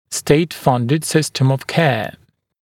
[ˌsteɪt’fʌndɪd ‘sɪstəm əv keə][ˌстэйт’фандид ‘систэм ов кэа]финансируемая государством система здравоохранения